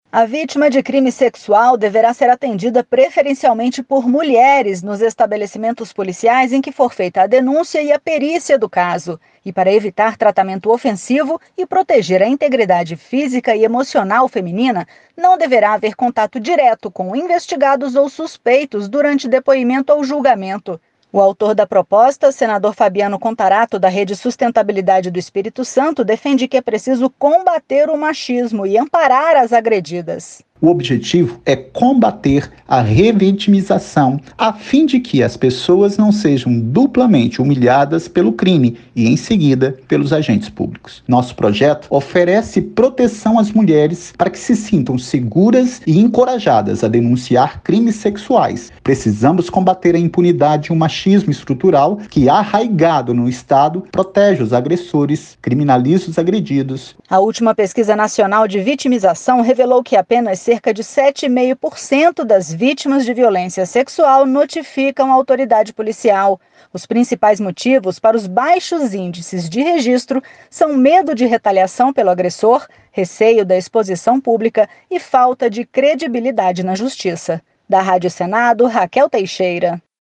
As informações com a repórter